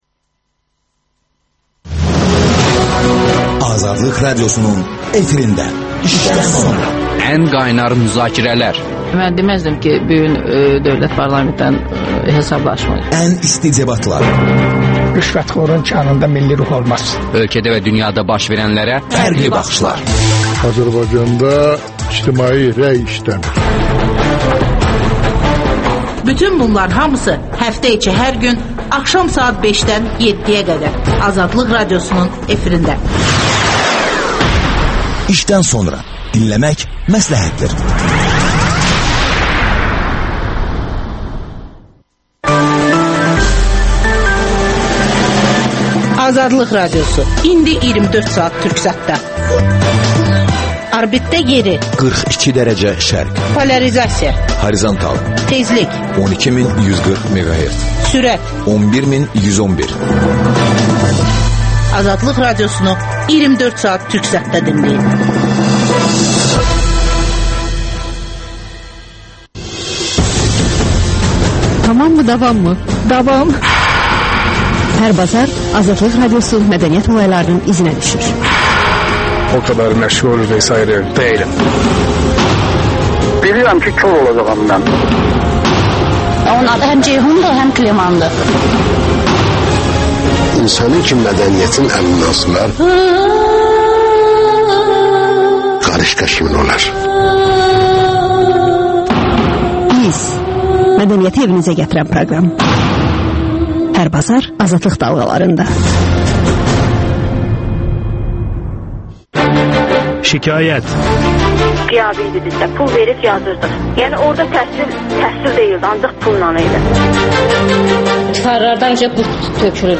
İşdən sonra - Nazirlik rəsmisi canlı efirdə...